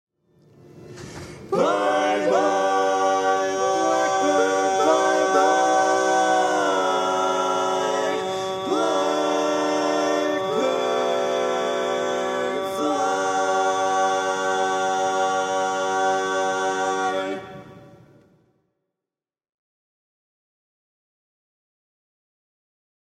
Key written in: G Major
Type: Barbershop